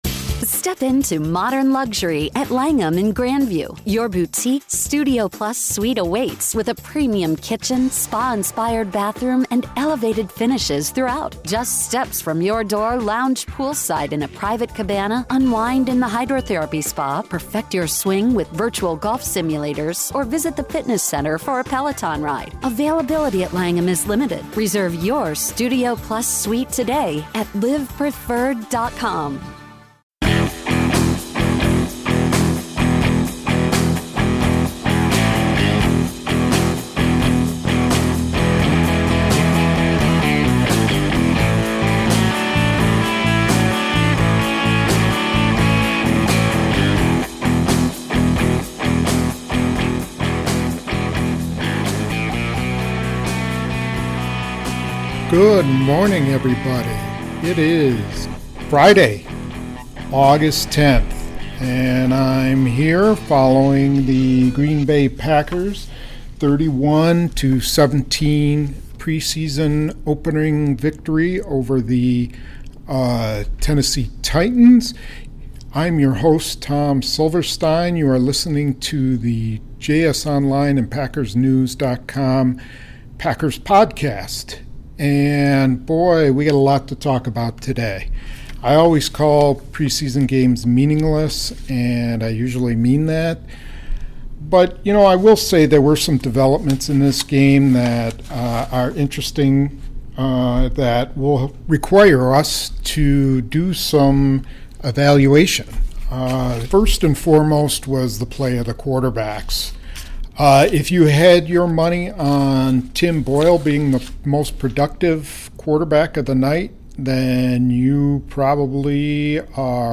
In the "Say What?" feature, coach Mike McCarthy addresses QB Aaron Rodgers' criticism of the rookie receivers in practice last week.